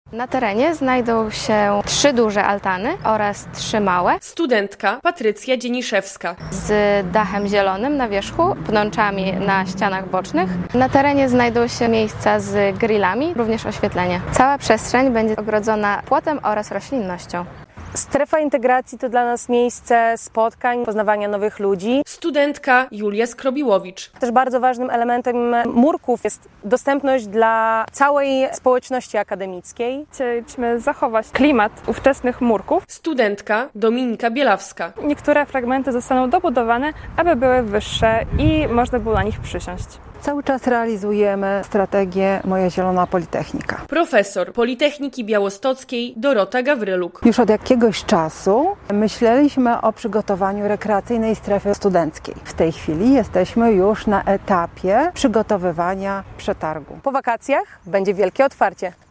Na terenie Politechniki Białostockiej powstaje Rekreacyjna Strefa Studenta - relacja